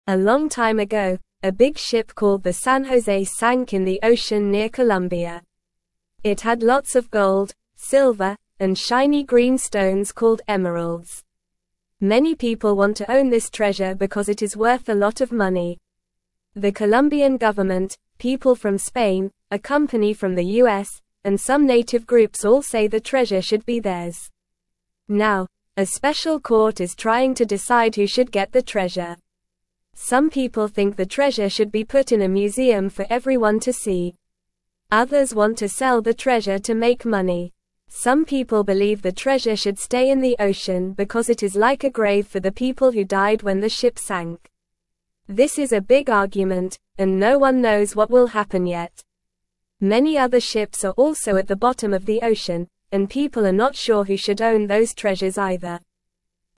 Normal
English-Newsroom-Beginner-NORMAL-Reading-The-Big-Ship-Treasure-Who-Should-Own-It.mp3